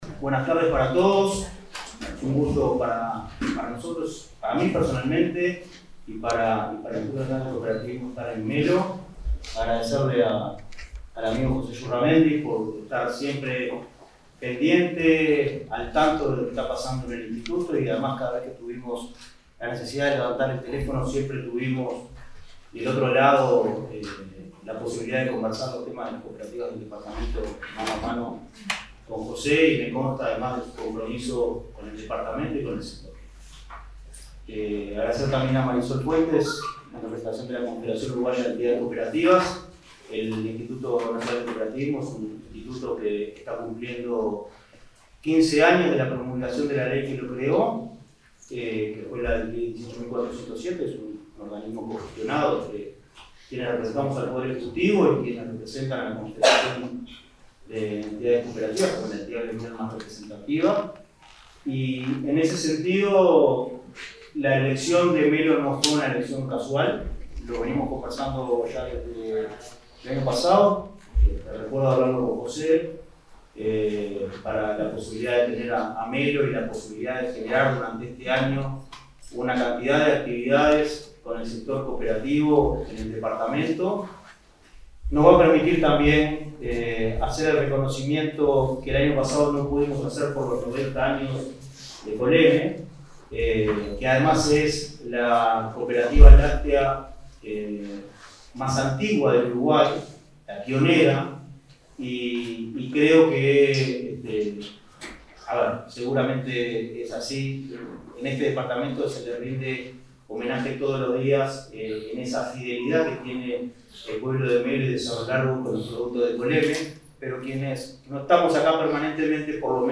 Palabras del presidente de Inacoop y el intendente de Cerro Largo
Palabras del presidente de Inacoop y el intendente de Cerro Largo 21/04/2023 Compartir Facebook X Copiar enlace WhatsApp LinkedIn En el marco del lanzamiento de Melo, como Capital Nacional del Cooperativismo, este 21 de abril, se expresaron el presidente de Instituto Nacional del Cooperativismo (Inacoop), Martín Fernández, y el intendente de Cerro Largo, José Yurramendi.